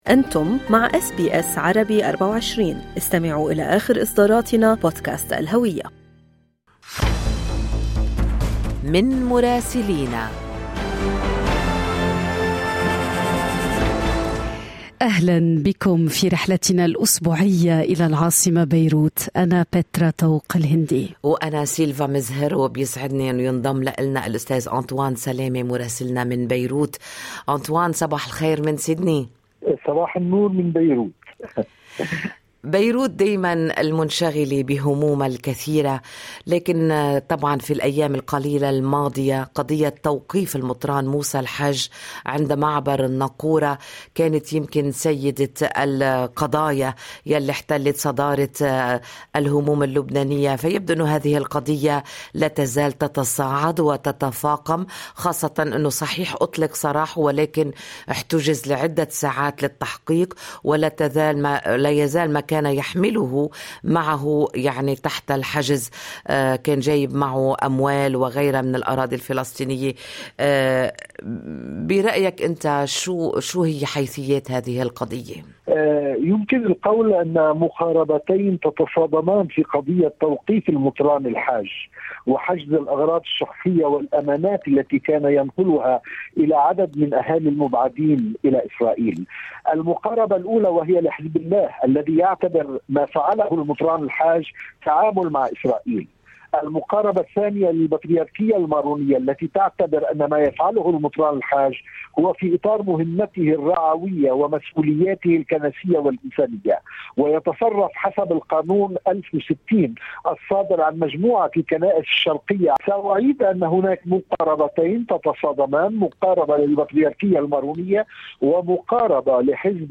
من مراسلينا: أخبار لبنان في أسبوع 26/7/2022